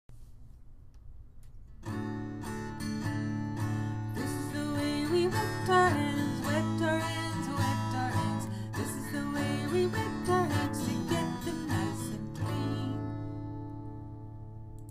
Tune: "Here We Go Round the Mulberry Bush"